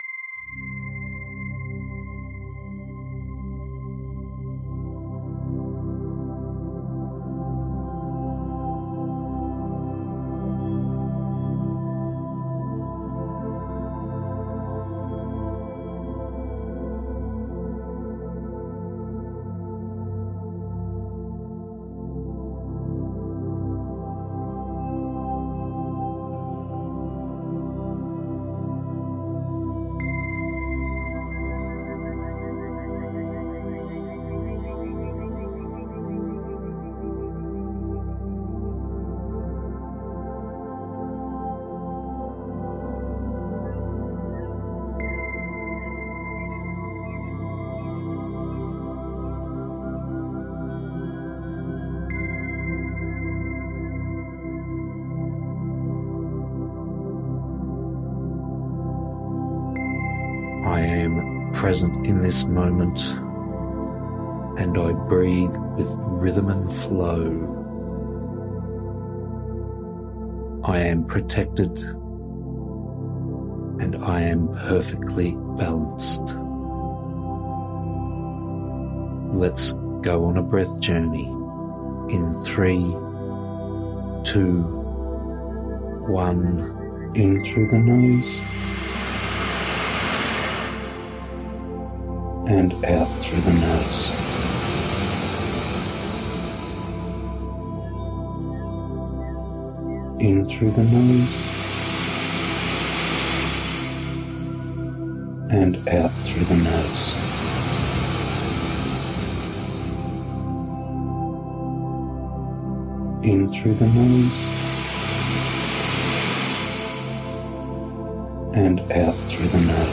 Guided Mini Breathwork MP3s Samples | Find Your Rhythm | Lifetime Access
Altered States Breathing @ Inhale 5sec, Exhale 8sec
Example at ~4.4 breaths per minute: Inhale 5 seconds, Exhale 8 seconds
Inhale 3 sec… Pause 2 sec… Exhale 5 sec… Pause 3 sec
DEMO-4-5-8-FibonaQi.mp3